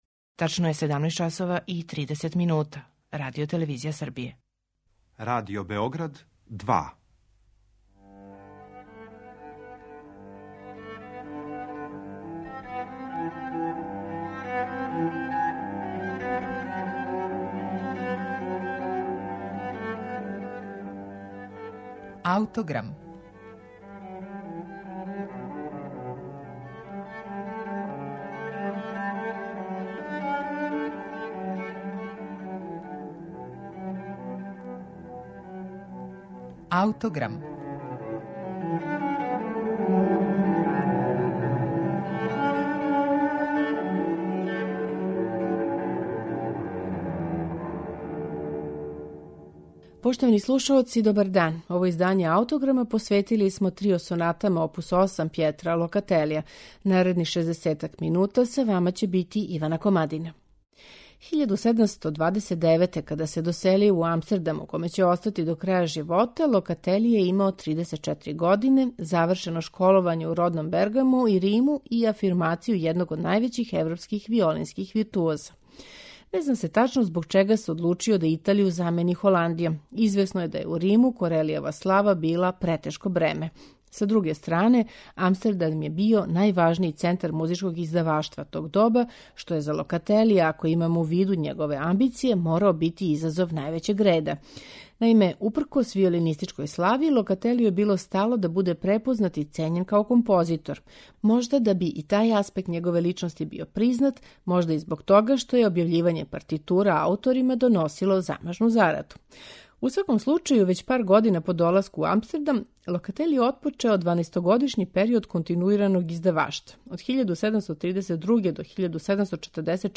виолине
виолончело
чембало.